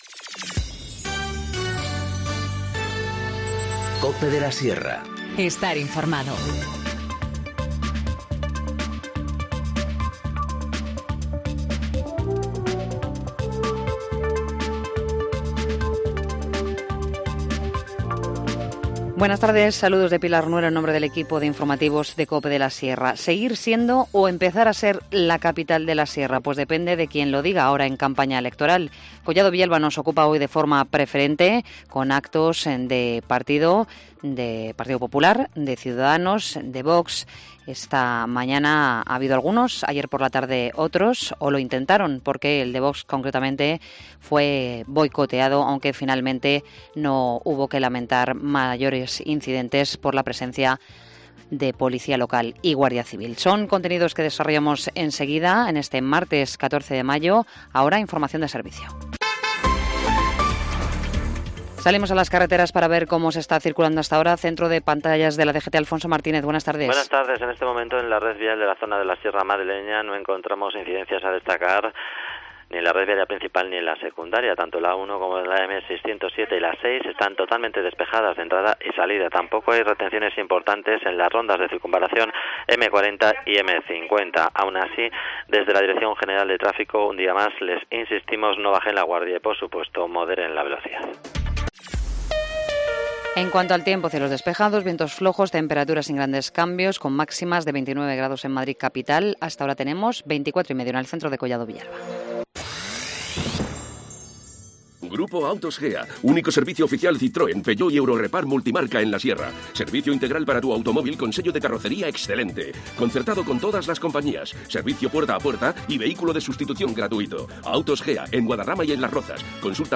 Informativo Mediodía 14 mayo 14:20h